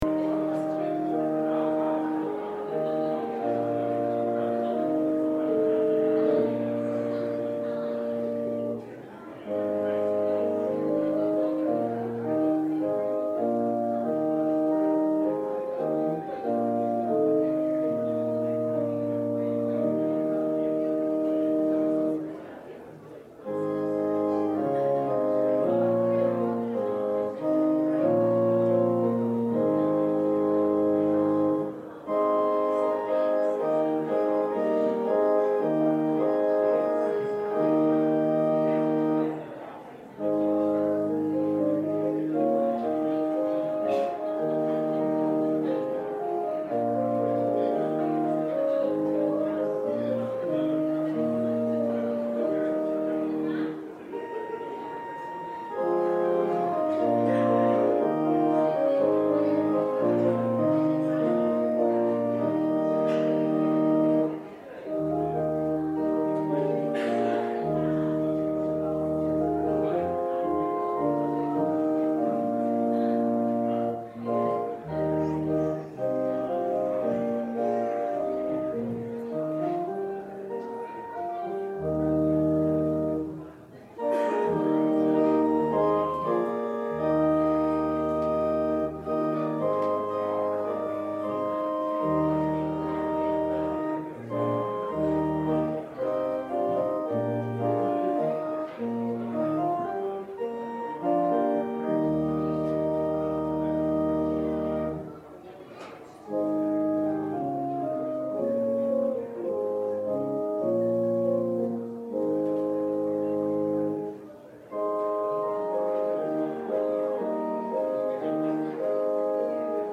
39 Service Type: Sunday Worship A Valentine's Special